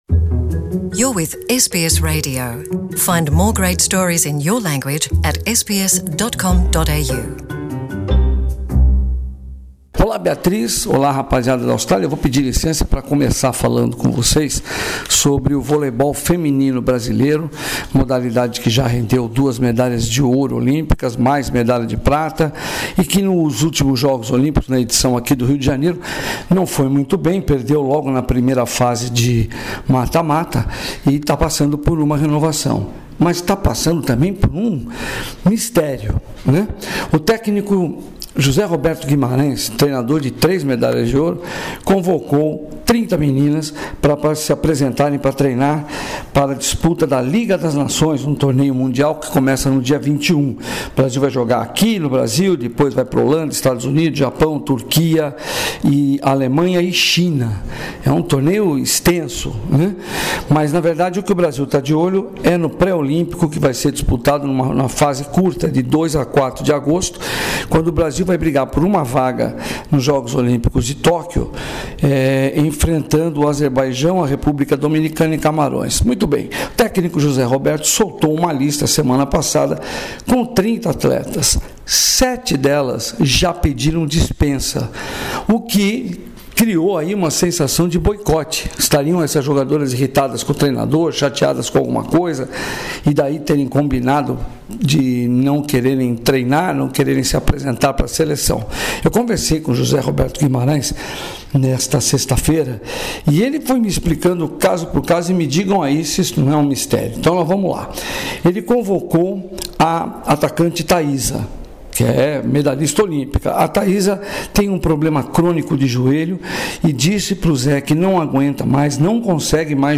Ouça a reportagem do correspondente da SBS em português